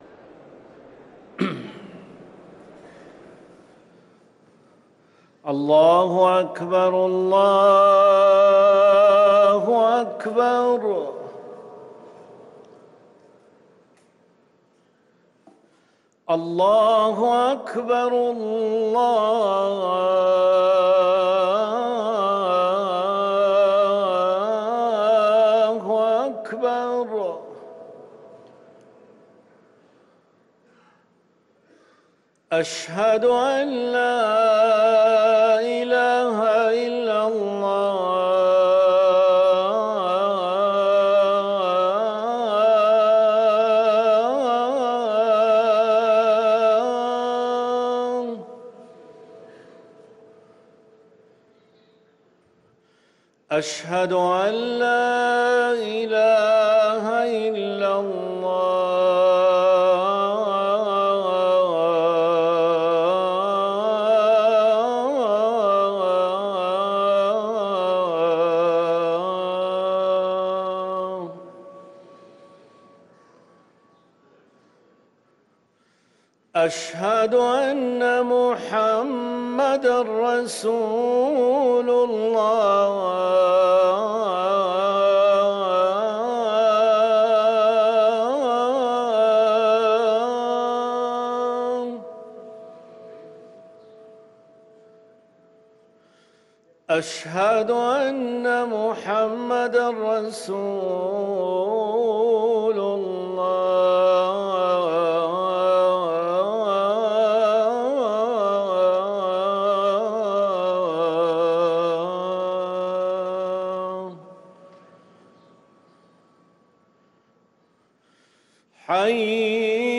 أذان الجمعة الأول